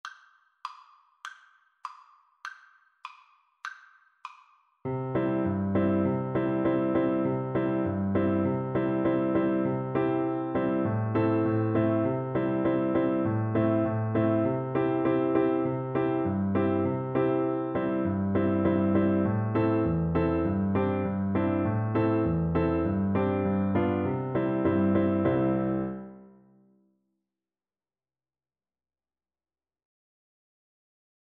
2/4 (View more 2/4 Music)
Moderato
Voice  (View more Beginners Voice Music)
Traditional (View more Traditional Voice Music)